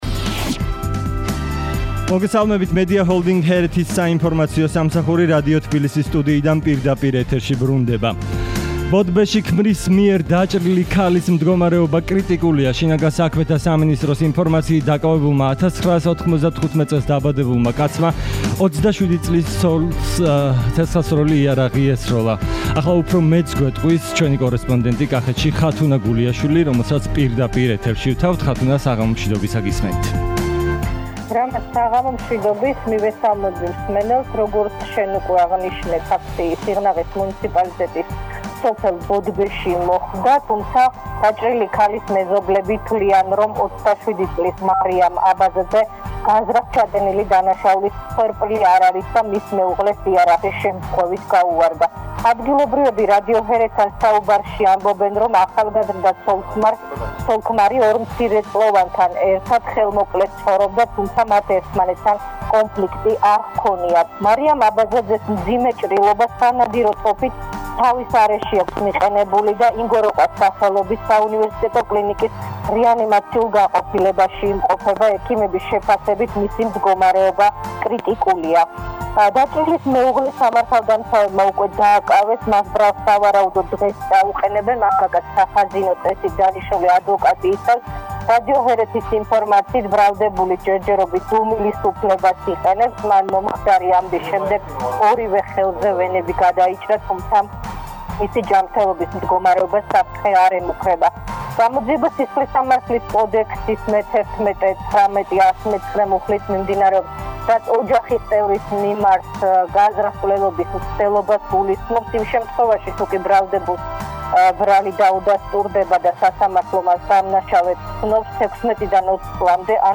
ახალი ამბები 17:00 საათზე – HeretiFM